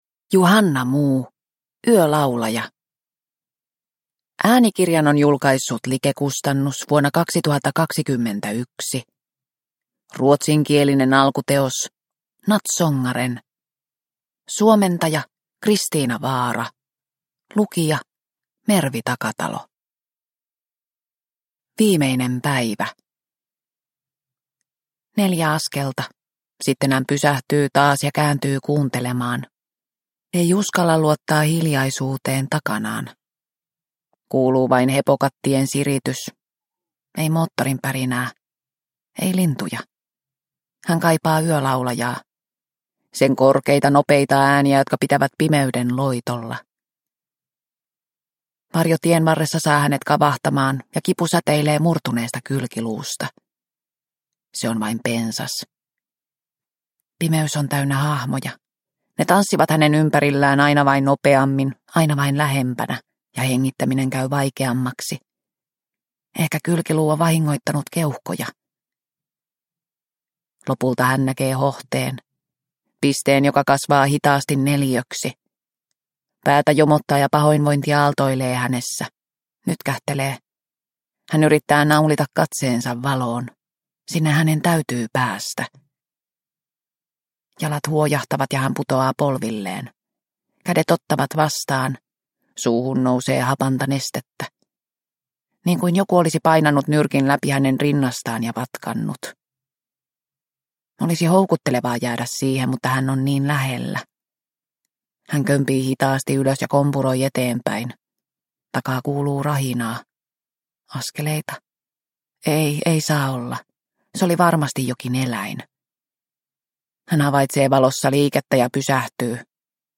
Yölaulaja – Ljudbok – Laddas ner